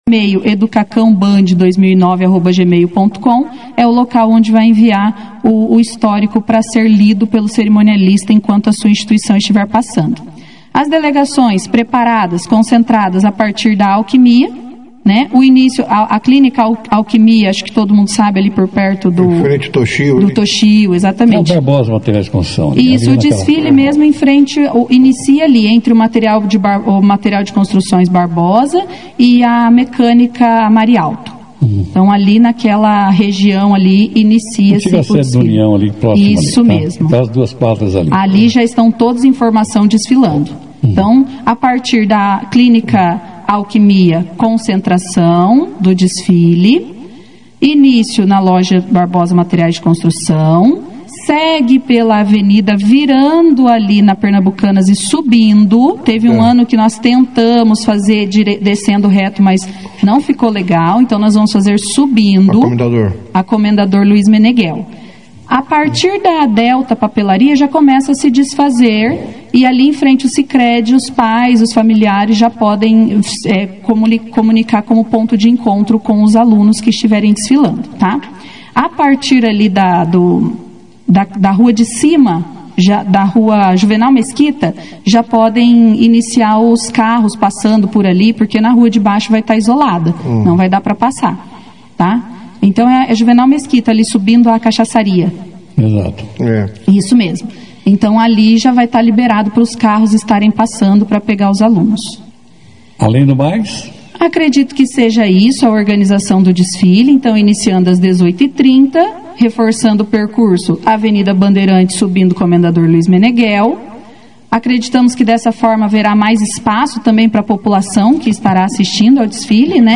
A secretária municipal de Educação de Bandeirantes, professora Aline Firmino das Neves Vasconcelos, participou nesta quarta-feira (20) da 2ª edição do Jornal Operação Cidade, onde apresentou detalhes do cronograma da Semana da Pátria no município.